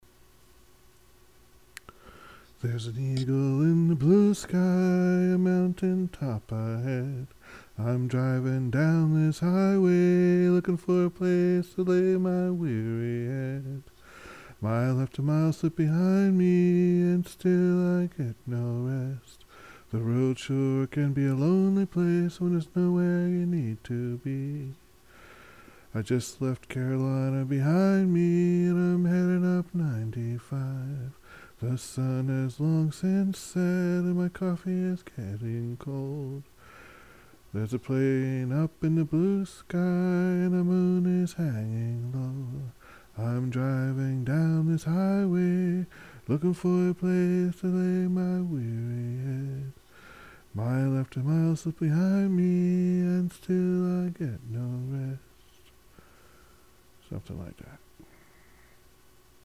Vocie recording #2. An Eagle in the blue sky